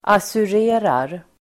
Ladda ner uttalet
assurera verb, insure Uttal: [asur'e:rar] Böjningar: assurerade, assurerat, assurera, assurerar Definition: försäkra (en postförsändelse) (insure (an item of mail)) Exempel: assurerad post (insured mail)